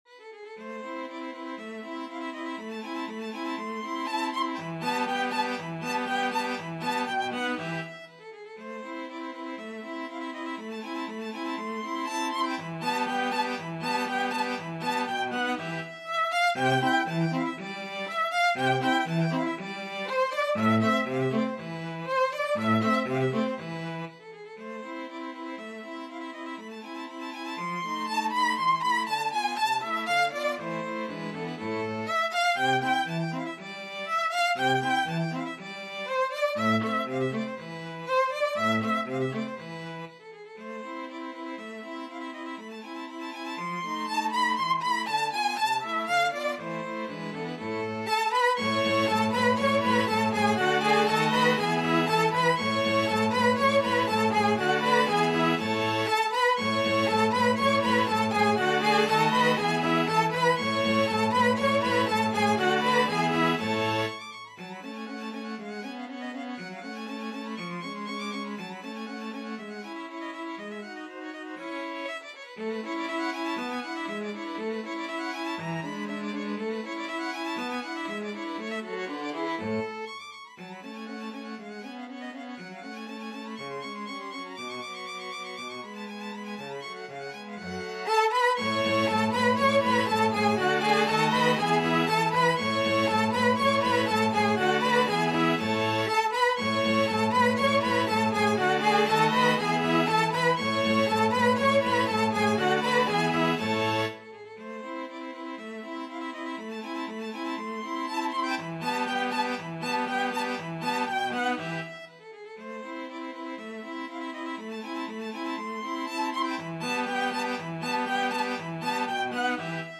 Free Sheet music for String Quartet
This is an arrangement for string quartet of the complete 3rd movement of the Piano sonata in A by Mozart labelled "in the Turkish Style"
2/4 (View more 2/4 Music)
A minor (Sounding Pitch) (View more A minor Music for String Quartet )
Allegro con brio (View more music marked Allegro)
Classical (View more Classical String Quartet Music)